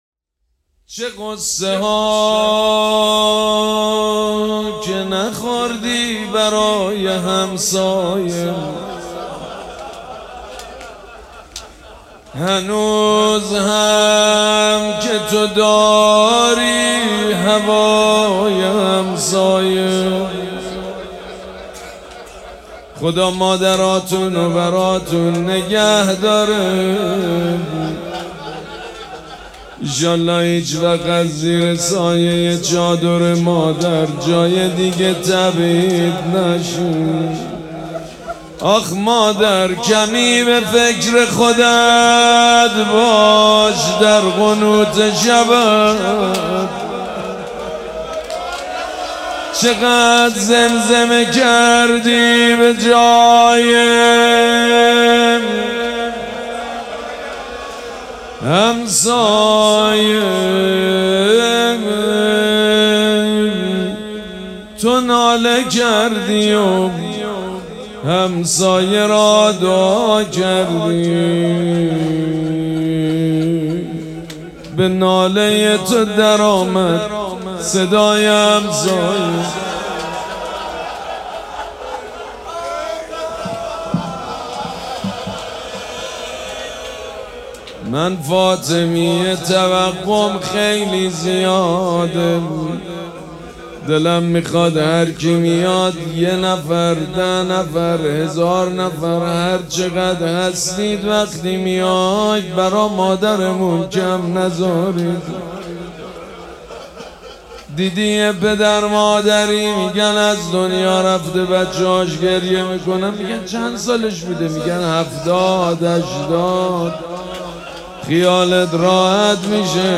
شب اول مراسم عزاداری دهه دوم فاطمیه ۱۴۴۶
حسینیه ریحانه الحسین سلام الله علیها
روضه